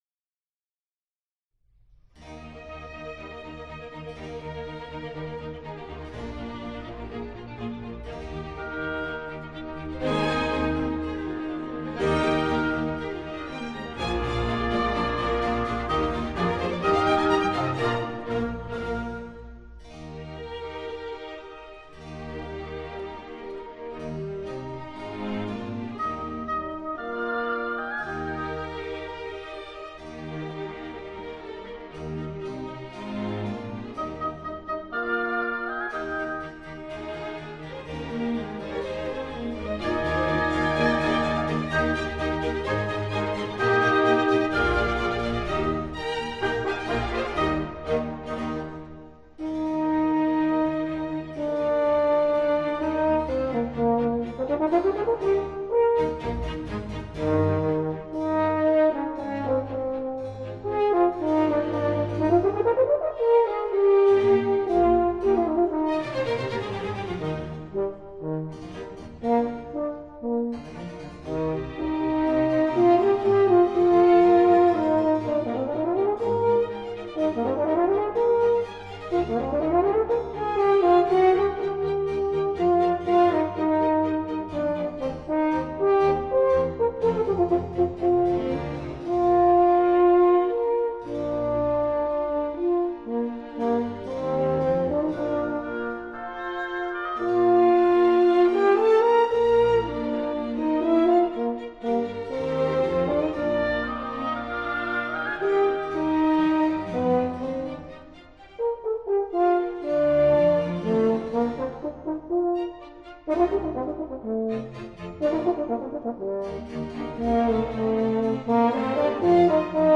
Mozart_ Horn Concertos.mp3